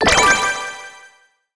SFX item_drop_armor.wav